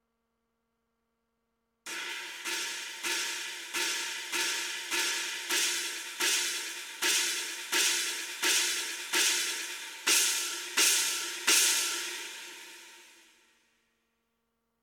Das 4. Crash (16" Sabian HH Thin Crash / 19" Paragon Chinese "Spock"), augelöst innerhalb eines bestimmten Velocitywerts, 66-106, beinhaltet ein Klicken, das da wohl nicht hingehört. Es ist fast nur auf dem linken Kanal zu hören.
Auf der beigefügten Aufnahme sind einige Anschläge unterhalb dieser Schwelle (sauber), einige drin (mit klicken), und einige drüber (sauber). Mit Kopfhörer hört man das, also ich jedenfalls.